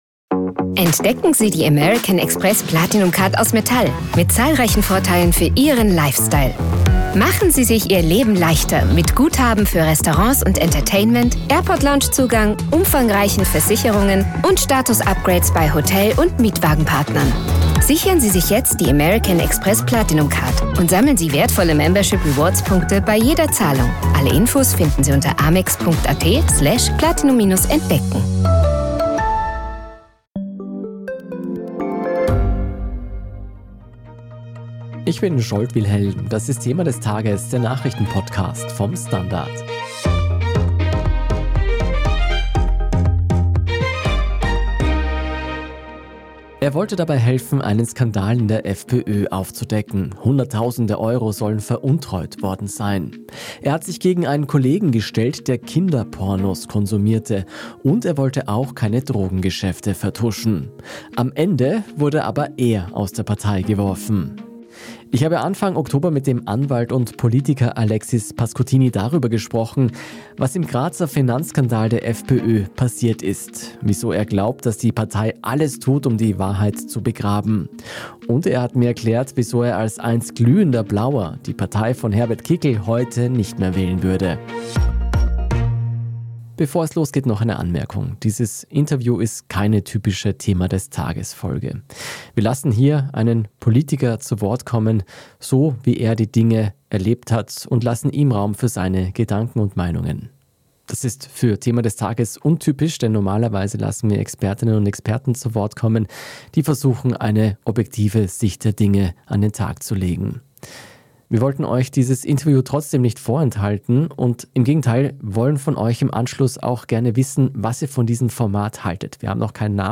Ich habe Anfang Oktober mit dem Anwalt und Politiker Alexis Pascuttini darüber gesprochen, was im Grazer Finanzskandal der FPÖ passiert ist, wieso er glaubt, dass die Partei alles tut, um die Wahrheit zu begraben.